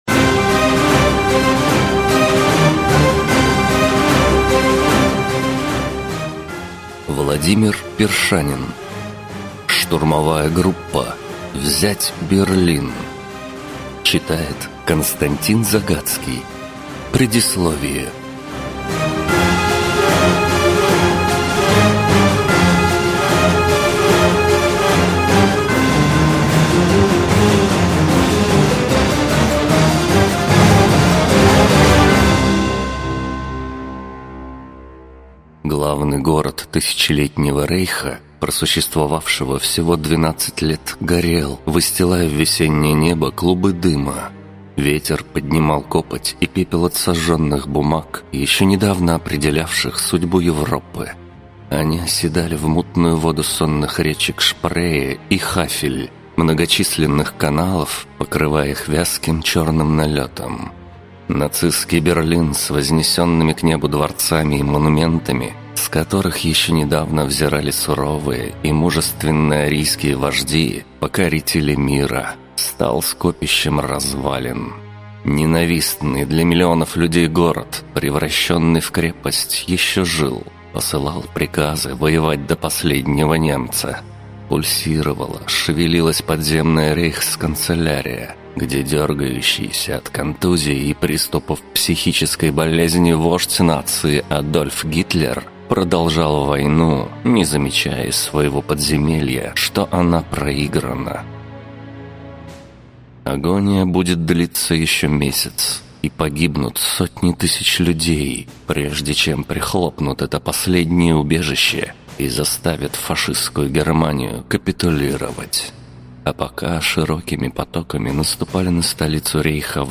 Аудиокнига Штурмовая группа. Взять Берлин!
Прослушать и бесплатно скачать фрагмент аудиокниги